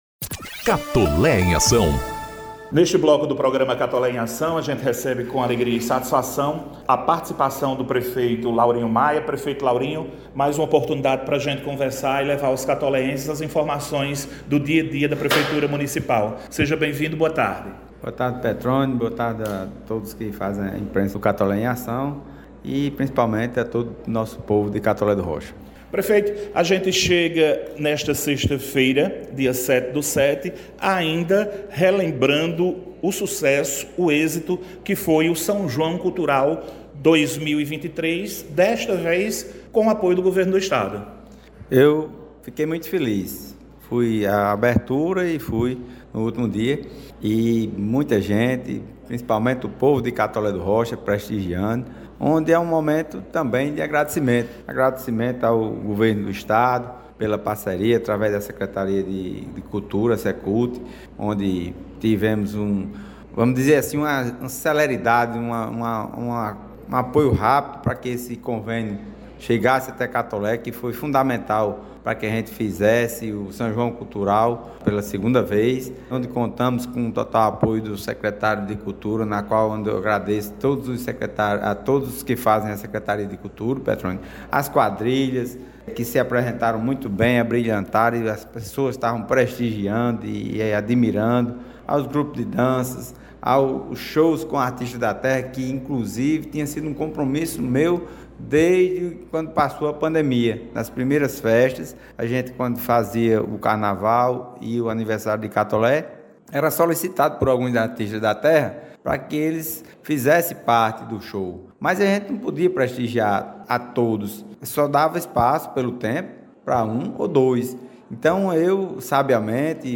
O Programa Institucional “Catolé em Ação” – edição n° 96 – recebeu, sexta-feira (07/07), o prefeito Laurinho Maia para falar sobre as ações, serviços e obras da administração municipal.